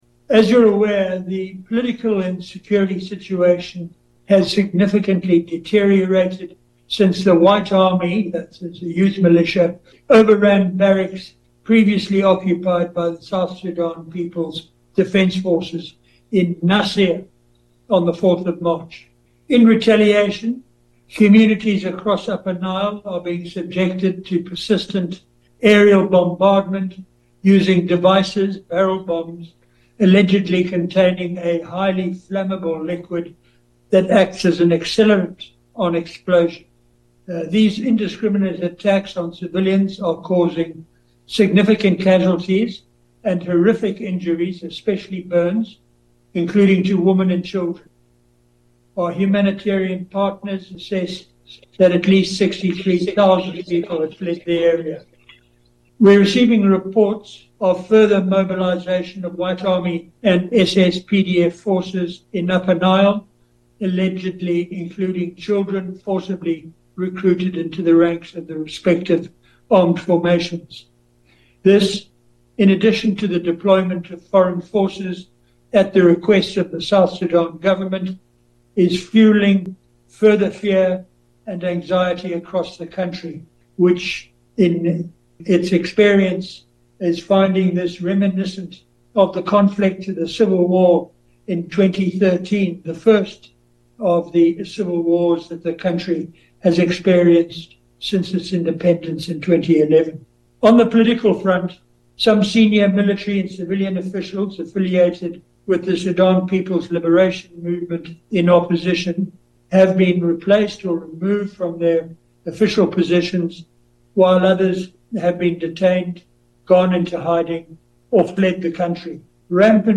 Statement by UN Special Representative and Head of UNMISS at UN Headquarters Noon Briefing
In a virtual press briefing to New York, Mr Haysom updated on the security and political situation in the country and said the only one path out of the cycle of conflict is to return to the Revitalized Peace Agreement, in letter and spirit. We bring you the Special Representative’s 5-minute statement to the Press.